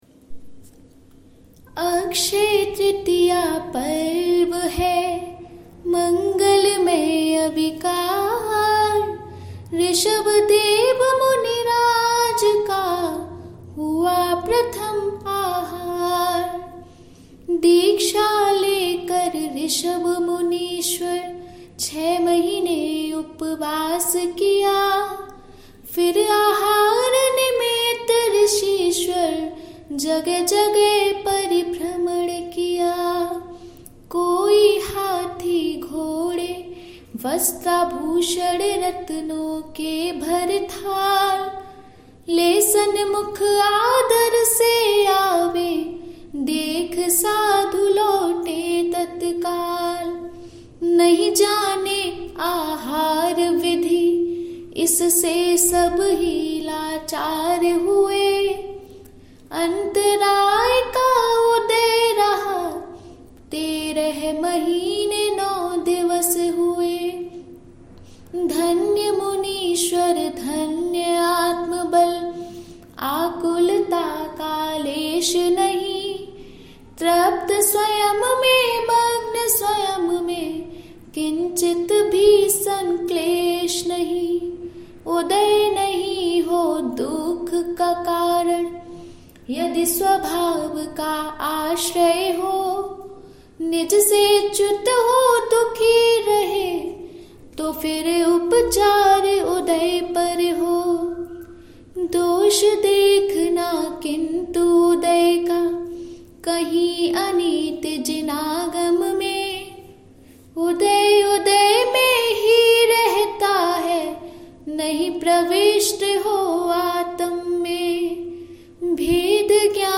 Type: Song
Particulars: AkshayTritiya Stavan